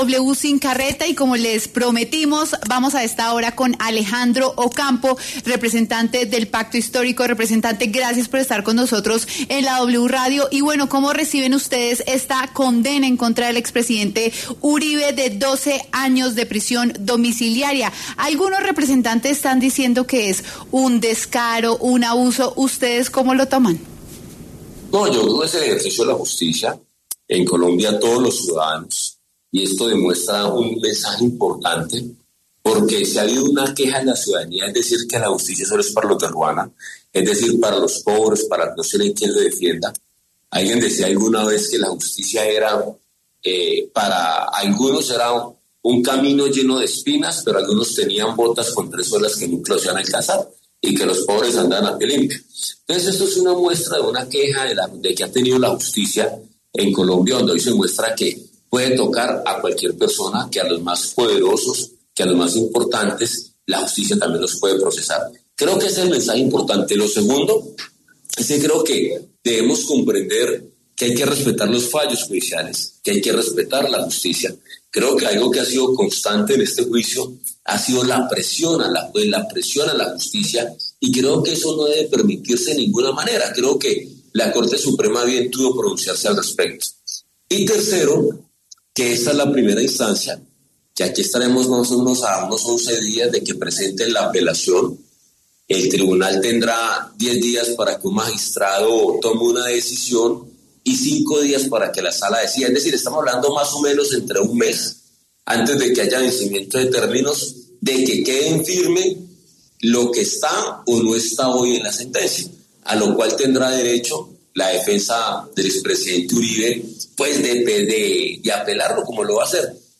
Alejandro Ocampo, representante del Pacto Histórico, pasó por los micrófonos de W Sin Carreta y habló de la sentencia impuesta por la juez Sandra Heredia en contra del expresidente Álvaro Uribe.